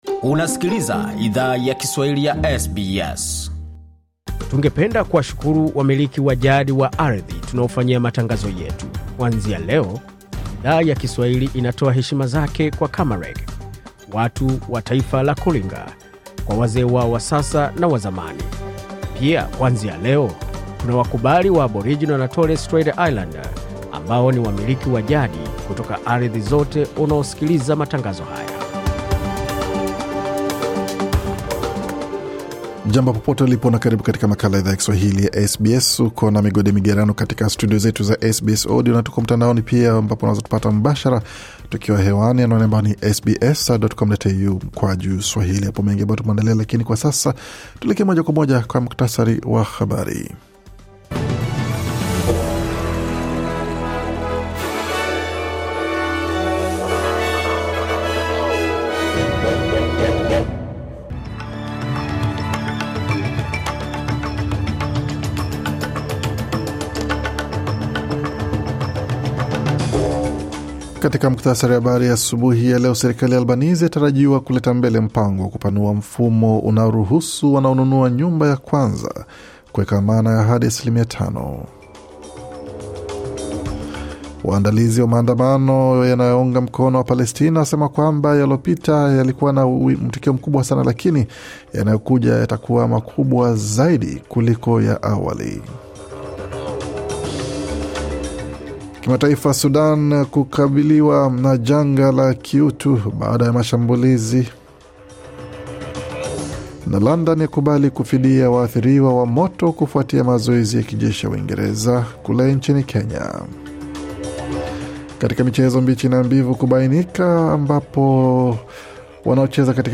Taarifa ya Habari 26 Agosti 2025